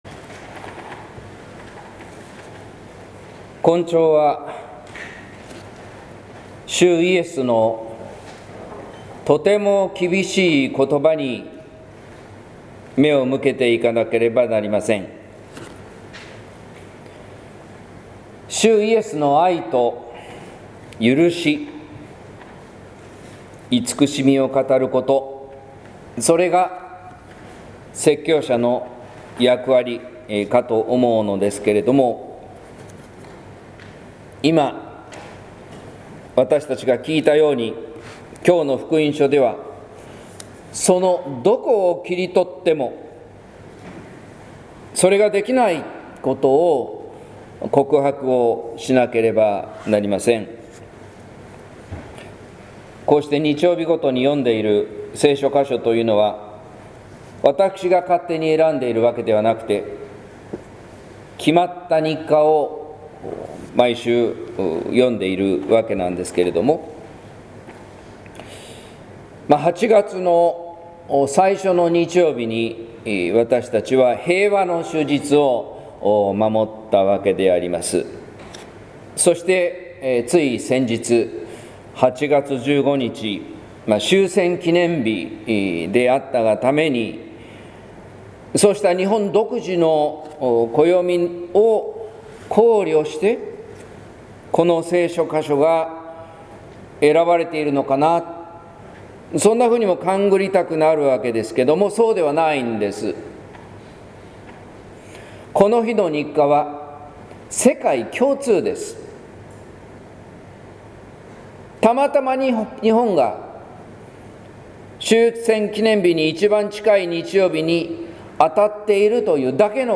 説教「十字架という洗礼」（音声版） | 日本福音ルーテル市ヶ谷教会